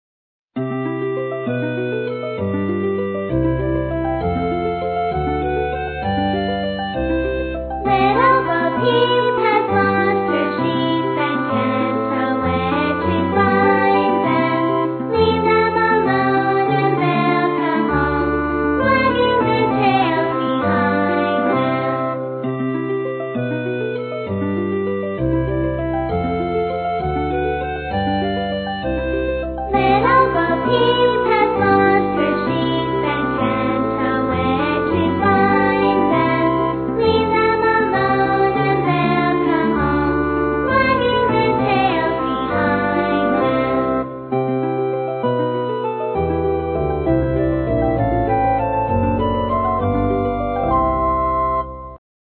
在线英语听力室英语儿歌274首 第120期:Little Bo Peep的听力文件下载,收录了274首发音地道纯正，音乐节奏活泼动人的英文儿歌，从小培养对英语的爱好，为以后萌娃学习更多的英语知识，打下坚实的基础。